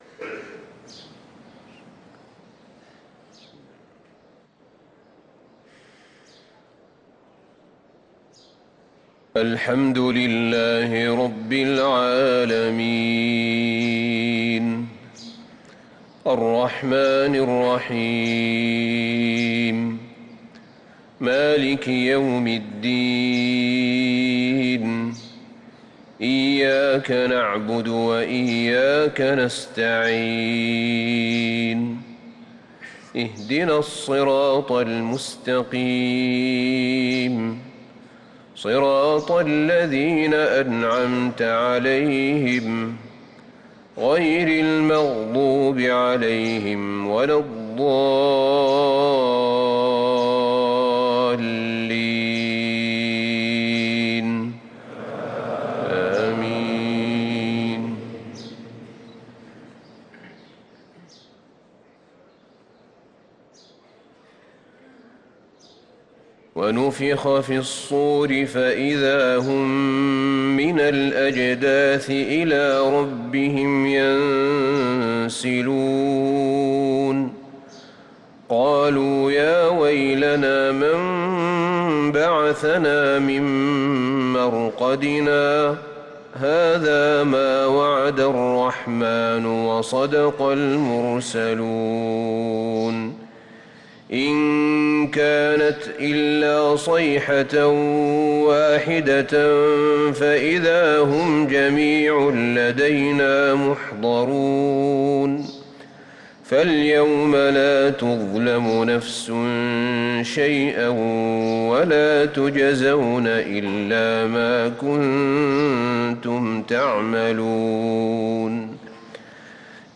صلاة الفجر للقارئ أحمد بن طالب حميد 19 ربيع الأول 1445 هـ
تِلَاوَات الْحَرَمَيْن .